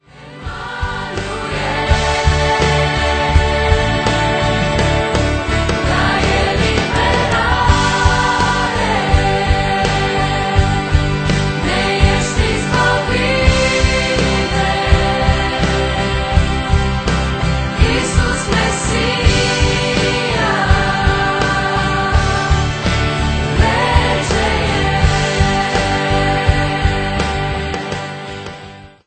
intr-un stil propriu si revigorant